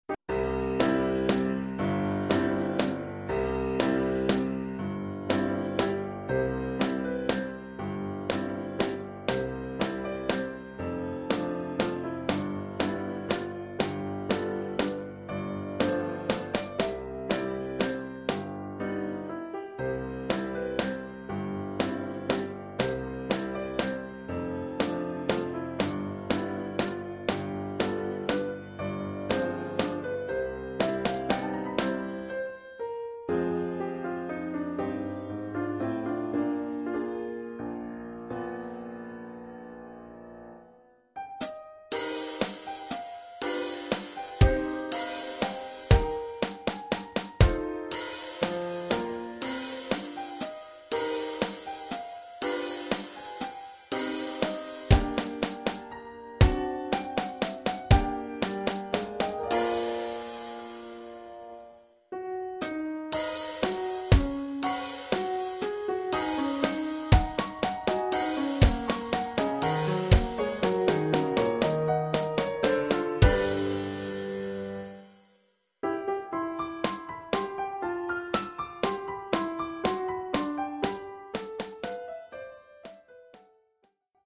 Oeuvre pour percussions et piano.
Niveau : débutant (1er cycle, 1ère année).
Caisse claire, grosse caisse, cymbale, hi-hat et piano.